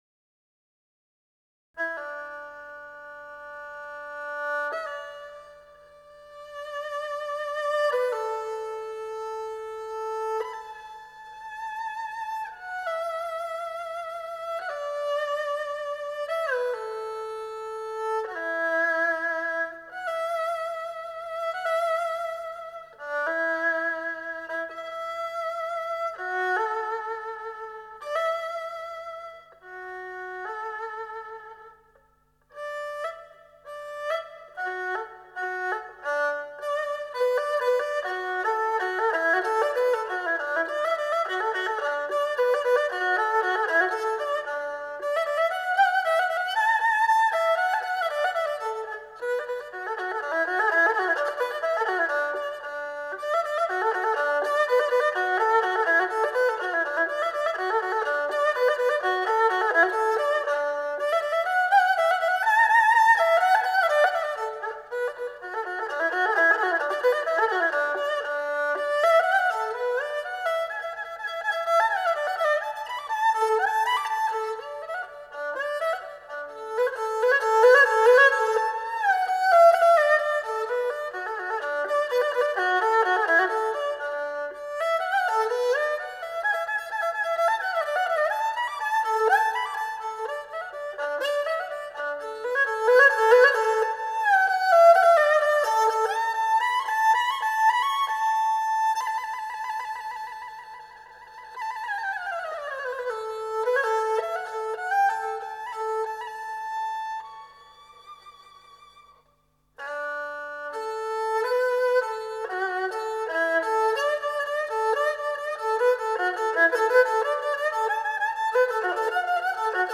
在北京、广州两地一流录音棚，采用数码录音设备精心录音制作而成
民乐遥远恬静，玄惑，蕴藏着丰富的遐想。